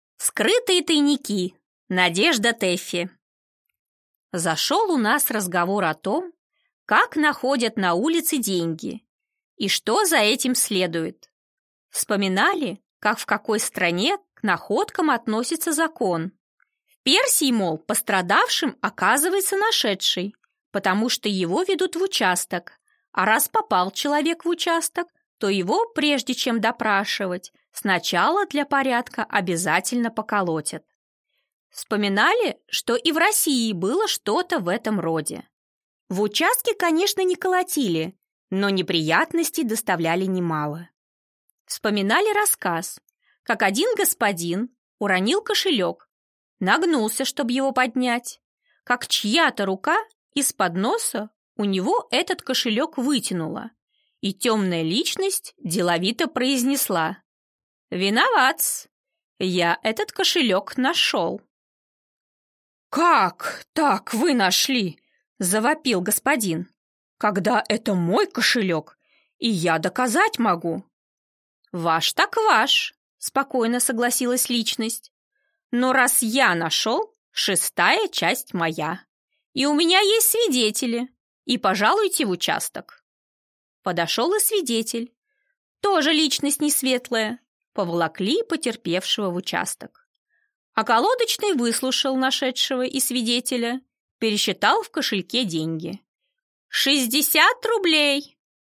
Аудиокнига Вскрытые тайники | Библиотека аудиокниг
Прослушать и бесплатно скачать фрагмент аудиокниги